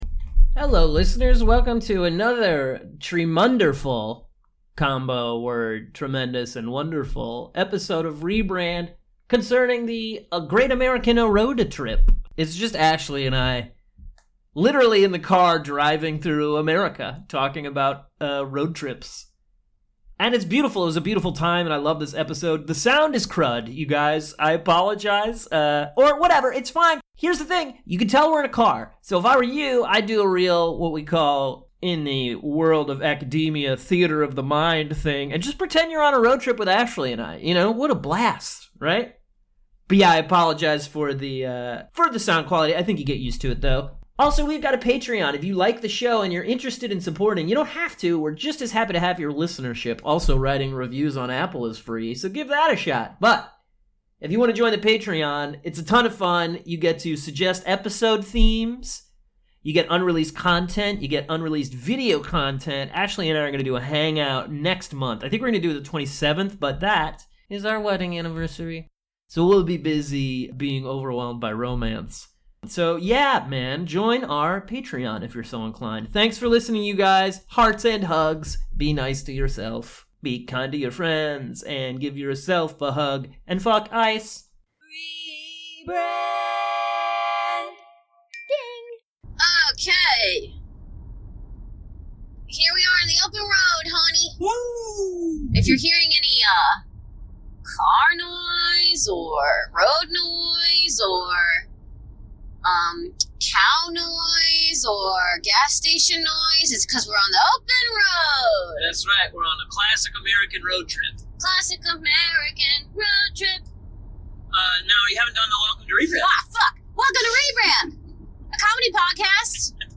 Also the sound is truly crud.